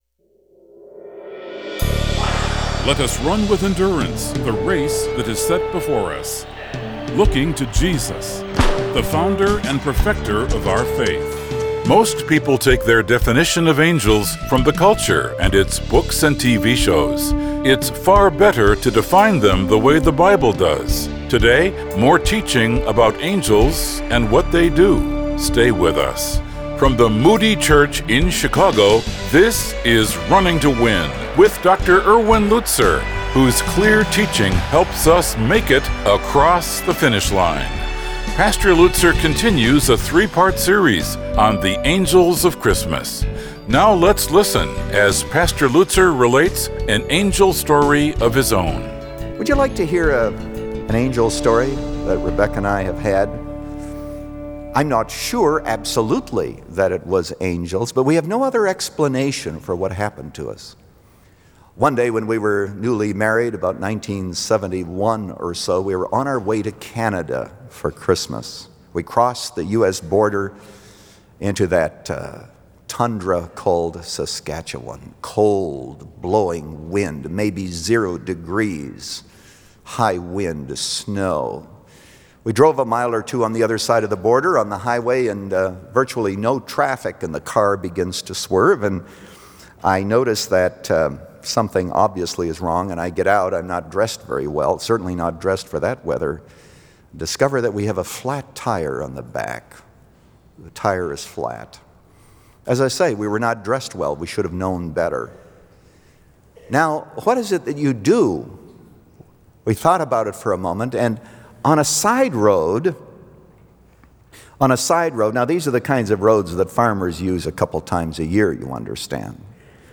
Since 1998, this 15-minute program has provided a Godward focus. Today this program broadcasts internationally in six languages.